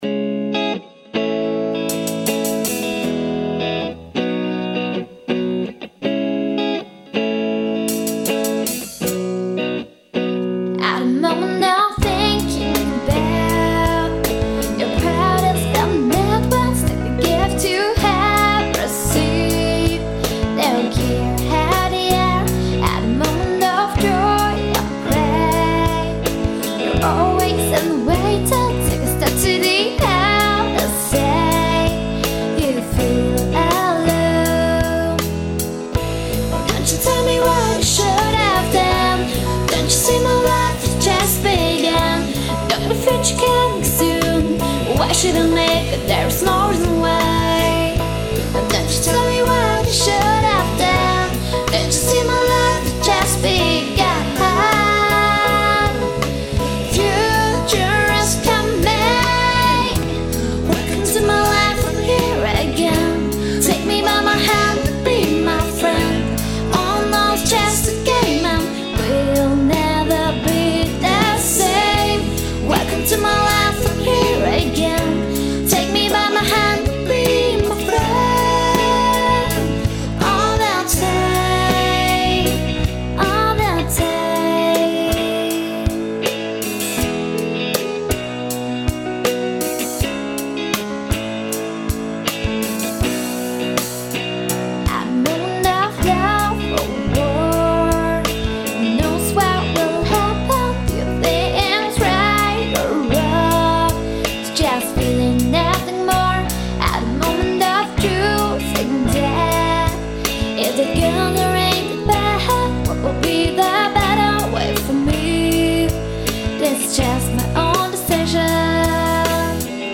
Pop/Rock-Band